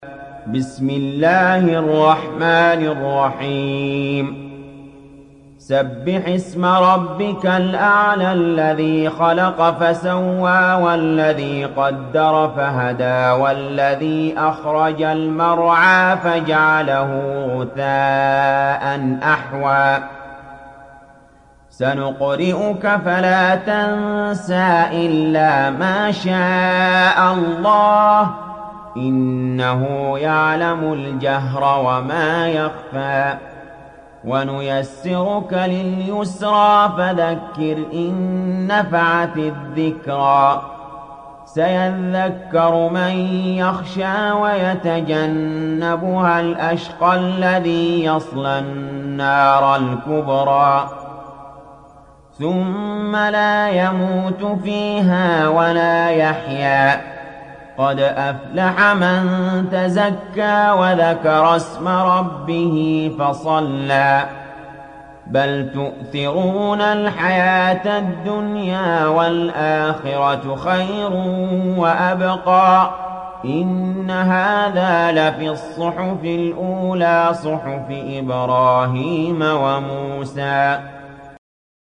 تحميل سورة الأعلى mp3 بصوت علي جابر برواية حفص عن عاصم, تحميل استماع القرآن الكريم على الجوال mp3 كاملا بروابط مباشرة وسريعة